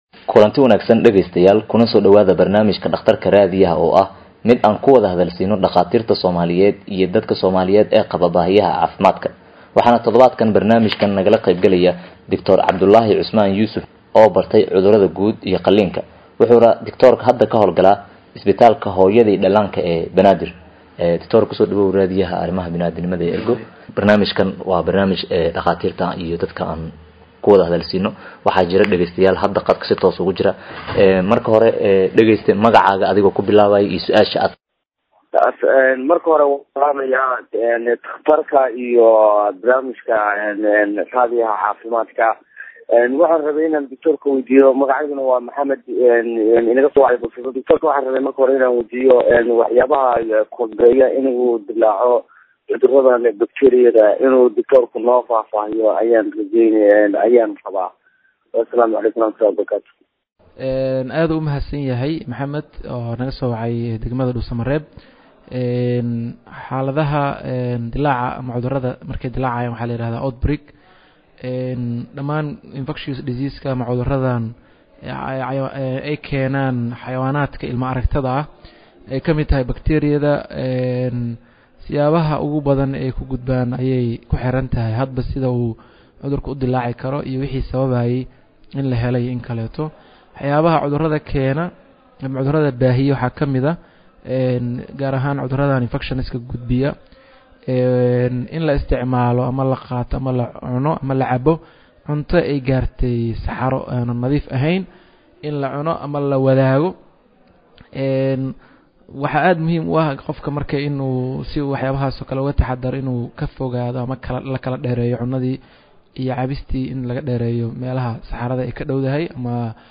Waa baraamij ay dhegeystayaasha idaacadda Ergo ay su’aalo caafimaad ku soo waydiiyaan dhaqaatiir kala duwan oo jooga dalka gudihiisa.